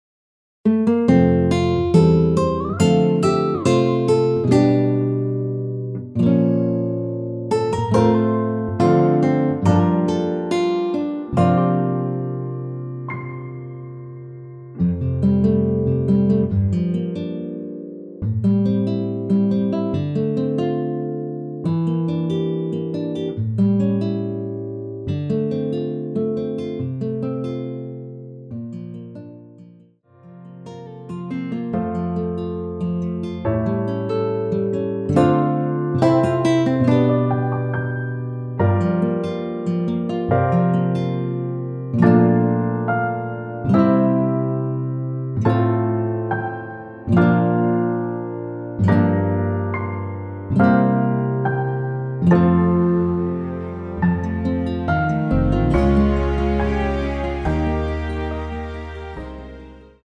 원키(1절+후렴)MR입니다.
앞부분30초, 뒷부분30초씩 편집해서 올려 드리고 있습니다.
중간에 음이 끈어지고 다시 나오는 이유는